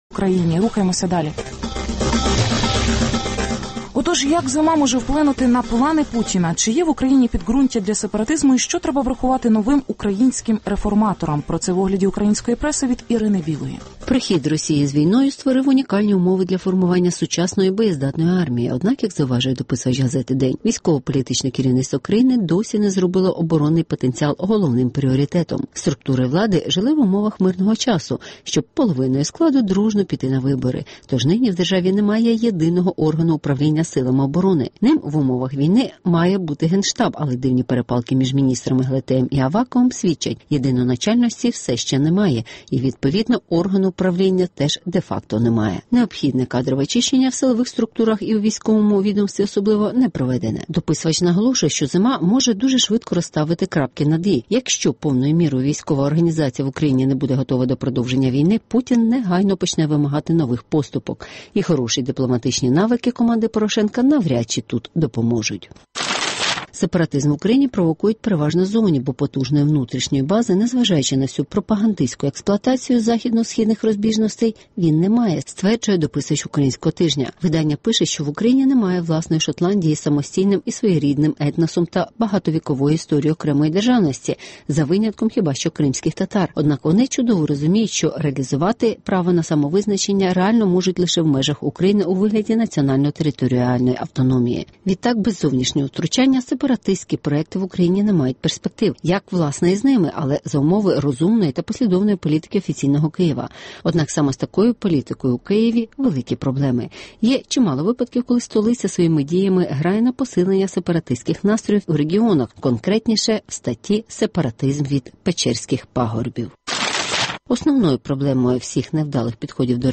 Зима в Україні «заморозить» агресію Путіна? (Огляд преси)